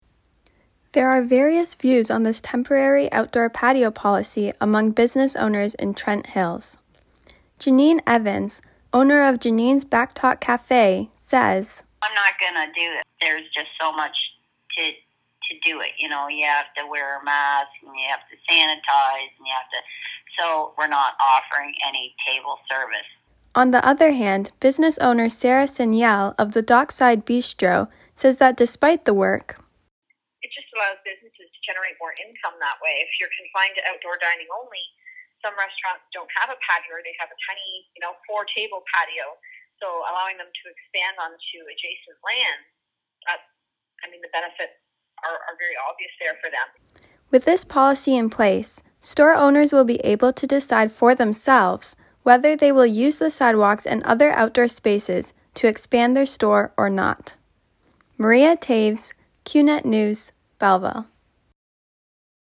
You can hear more audio from business owners in Trent Hills at the bottom of the story.